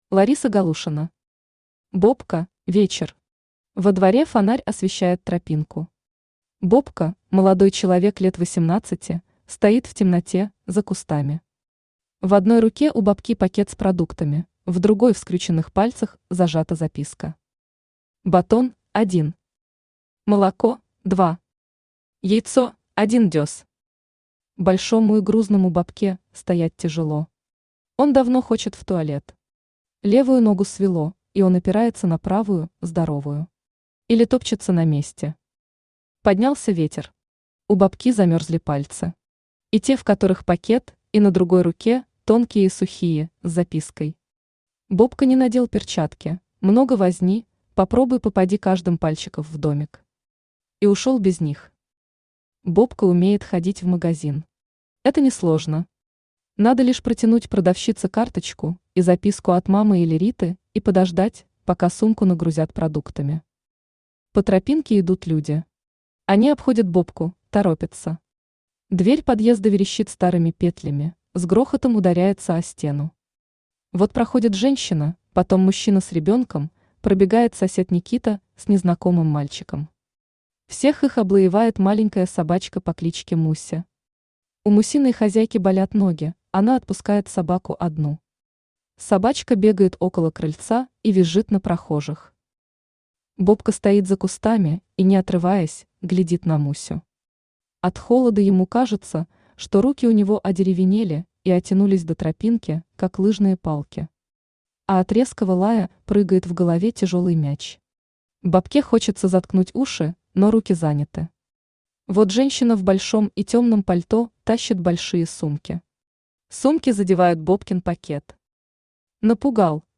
Аудиокнига Бобка | Библиотека аудиокниг
Aудиокнига Бобка Автор Лариса Галушина Читает аудиокнигу Авточтец ЛитРес.